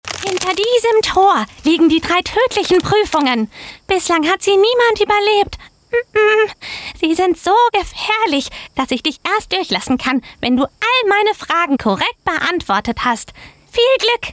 dunkel, sonor, souverän, markant, sehr variabel
Trickstimme
Audio Drama (Hörspiel), Children's Voice (Kinderstimme), Game, Scene, Lip-Sync (Synchron), Tale (Erzählung)